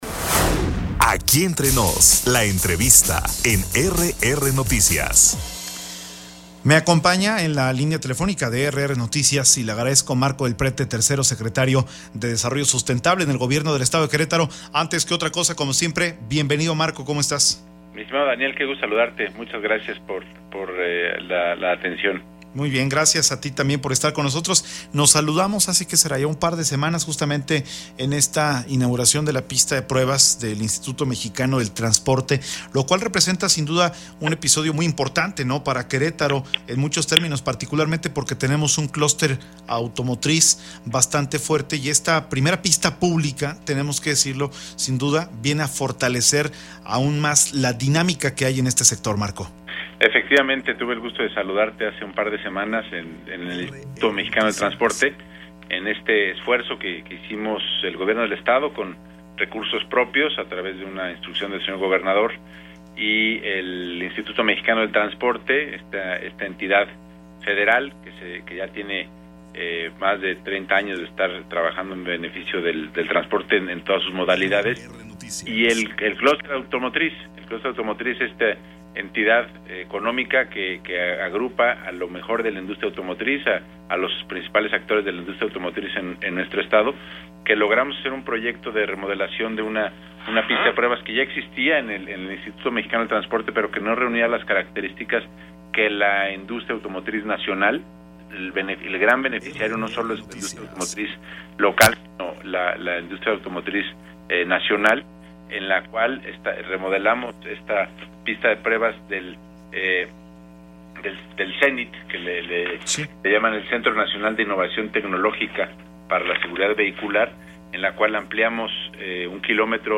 Entrevistas
Entrevista con Marco Del Prete Tercero Secretario de Desarrollo Sustentable
ENTREVISTA-MARCO-DEL-PRETE.mp3